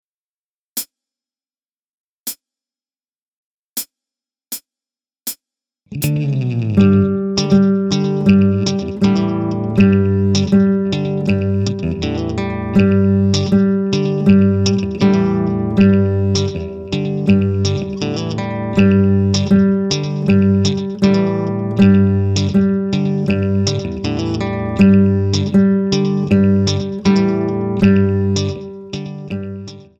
Teil 1 beginnt mit einstimmigem Spiel.